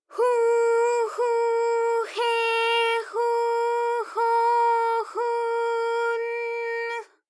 ALYS-DB-001-JPN - First Japanese UTAU vocal library of ALYS.
hu_hu_he_hu_ho_hu_n_h.wav